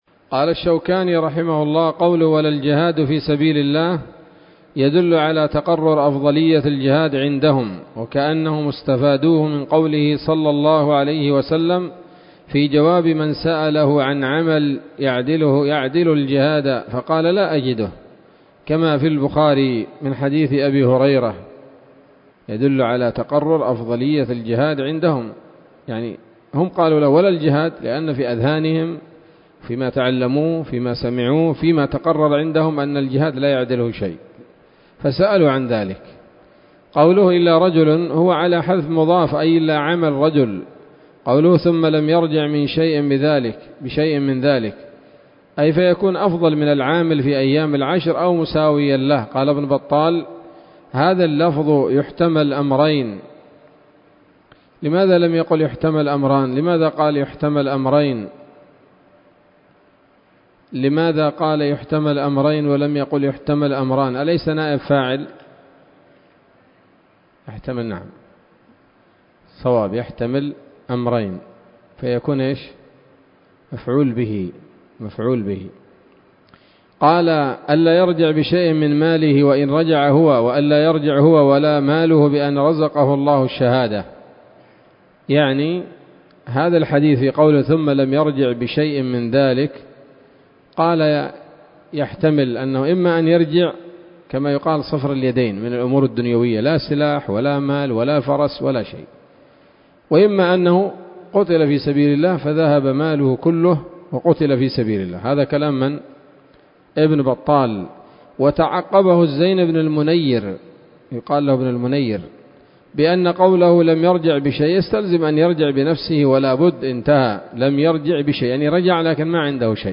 الدرس التاسع عشر من ‌‌‌‌كتاب العيدين من نيل الأوطار